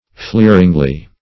fleeringly - definition of fleeringly - synonyms, pronunciation, spelling from Free Dictionary Search Result for " fleeringly" : The Collaborative International Dictionary of English v.0.48: fleeringly \fleer"ing*ly\, adv.
fleeringly.mp3